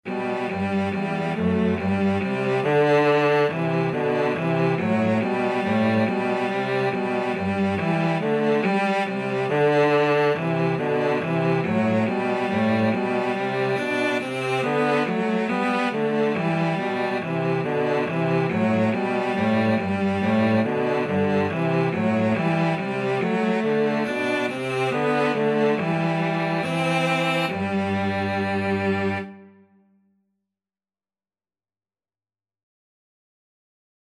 Cello 1Cello 2
G major (Sounding Pitch) (View more G major Music for Cello Duet )
2/2 (View more 2/2 Music)
h - c. 80 Quick ( = c. 70 )
Cello Duet  (View more Easy Cello Duet Music)
Traditional (View more Traditional Cello Duet Music)